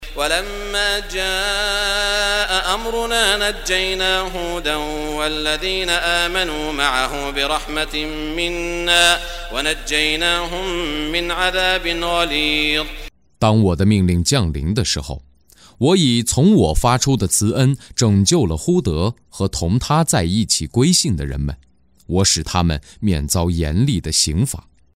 中文语音诵读的《古兰经》第（呼德章）章经文译解（按节分段），并附有诵经家沙特·舒拉伊姆的诵读